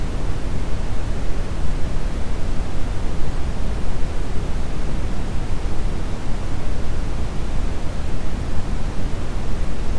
红噪声（Brown Noise）
不过，基于之前介绍的噪声分类原则，这个噪声在相当于可见光谱的红光一侧具有更高的能量密度，所以称红噪声也没有问题。
布朗噪声的低频能量比粉噪声还多，频谱中也能大概读出，频率增大一倍，能量减少约 6 dB。
红噪声-BrownNoise.mp3